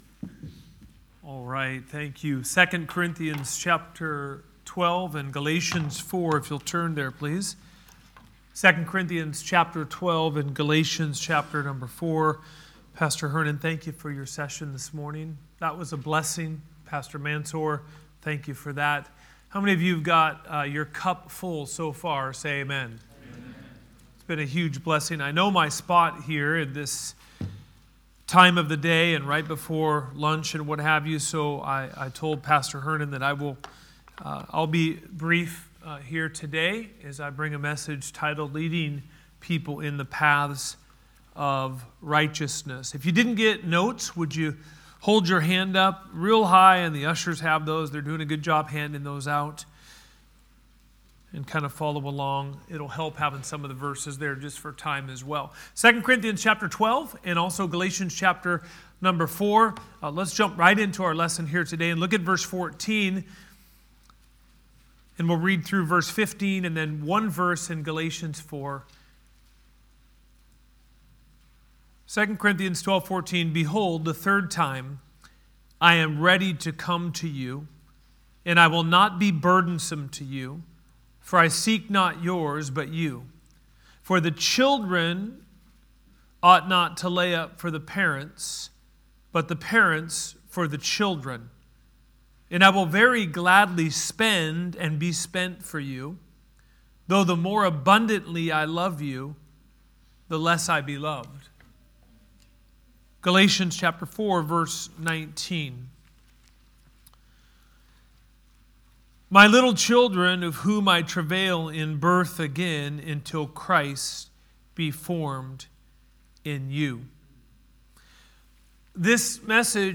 Sermons | Good Shepherd Baptist Church
Wed 3rd Session - Men Selah Conference 2026